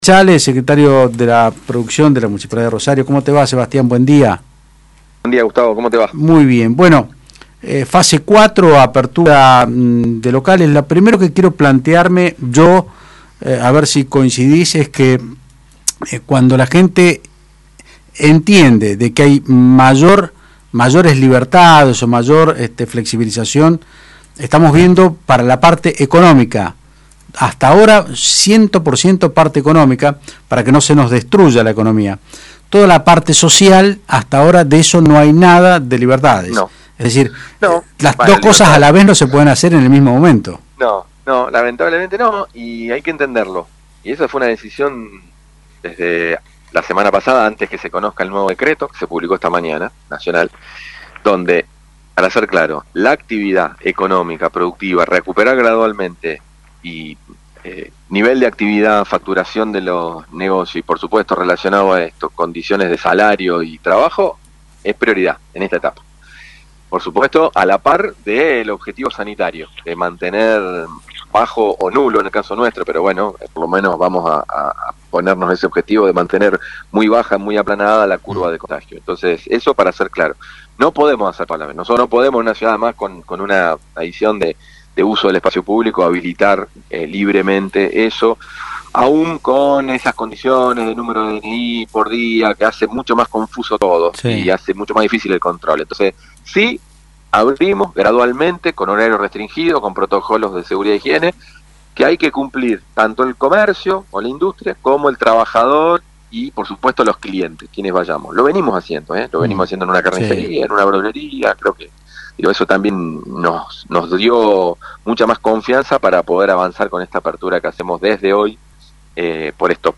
El Secretario de la Producción de Rosario Sebastián Chale explicó en Otros Ámbitos (Del Plata Rosario 93.5) como es la fase 4 de la cuarentena y cuales son los rubros y el protocolo a seguir.